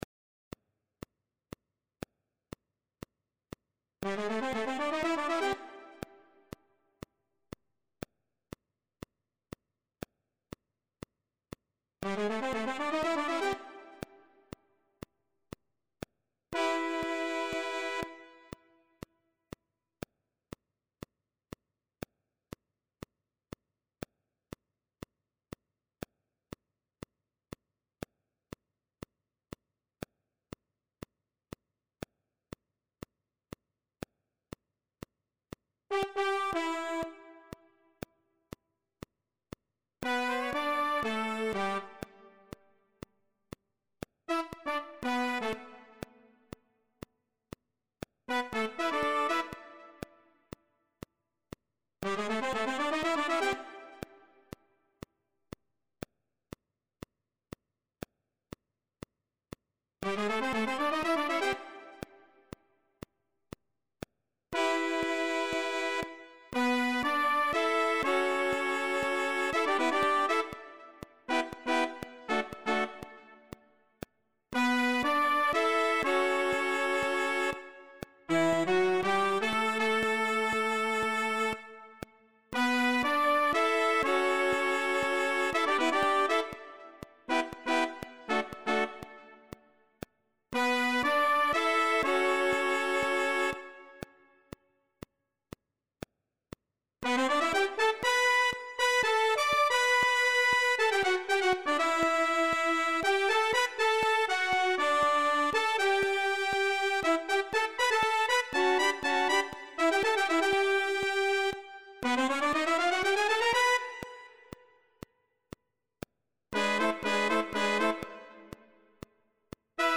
TOM ORIGINAL.
Partitura do Naipe de Metais para os seguintes instrumentos:
1. Sax Tenor;
2. Sax Alto;
3. Sax Barítono;
4. Trompete; e,
5. Trombone.